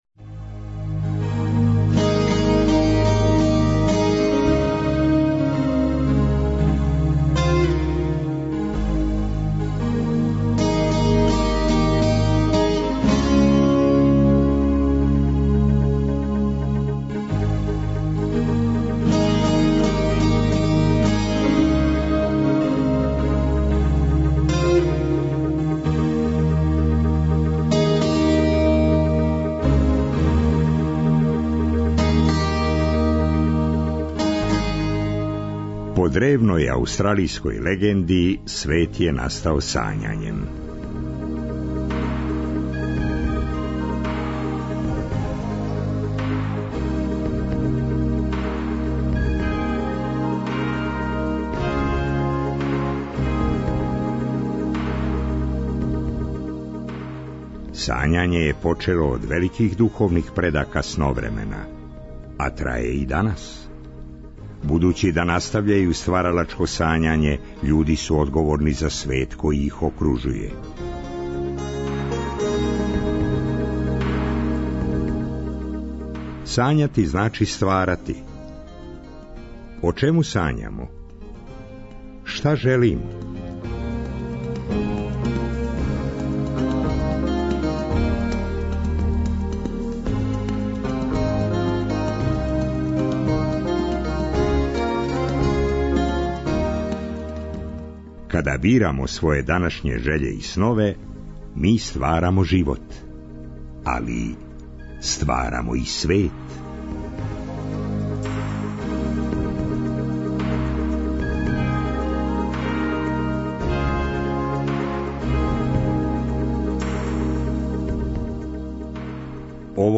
Представљамо нови пројекат независног позоришта "Ле Студио". На конференцији за новинаре одржаној 1. октобра